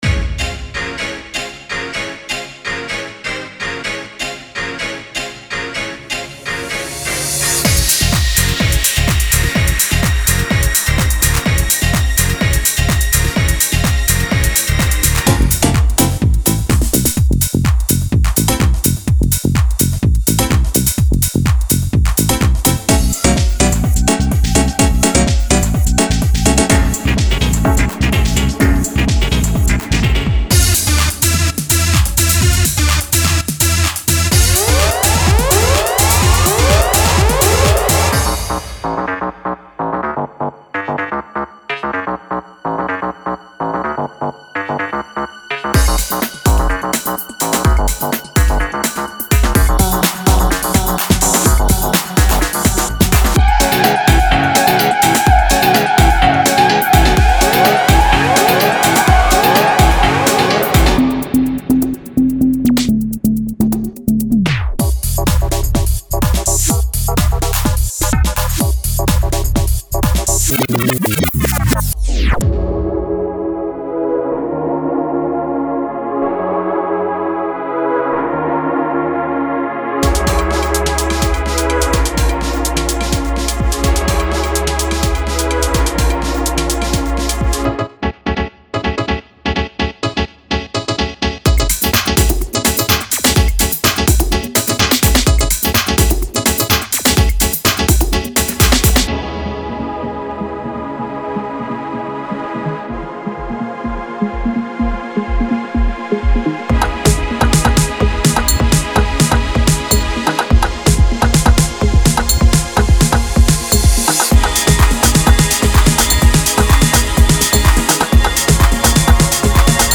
Genre:House
さらに、ピアノやエレクトリックキーボード、個性的なパーカッションのセレクションで楽曲に深みを加えられます。
デモサウンドはコチラ↓
73 Synth Loops
30 Bass Loops